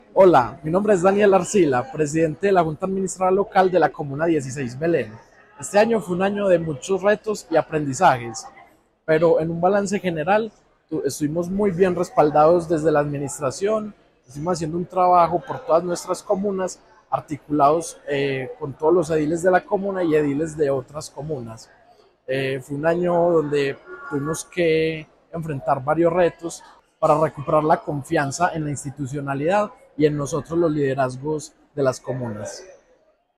Palabras de Daniel Arcila, edil de la comuna 16
Este martes, el Distrito celebró el Día de las Juntas Administradoras Locales (JAL), un espacio que resalta el compromiso y la labor de los ediles y edilas en las 16 comunas y cinco corregimientos de la ciudad.